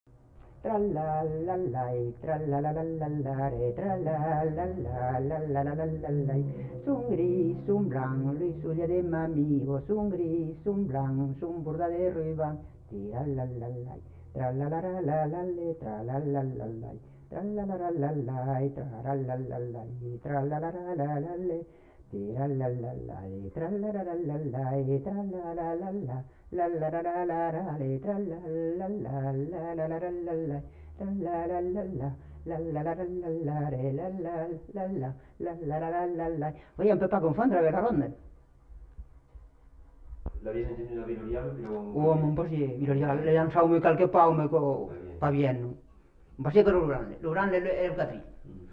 Aire culturelle : Haut-Agenais
Lieu : Castillonnès
Genre : chant
Type de voix : voix de femme
Production du son : chanté ; fredonné
Danse : bourrée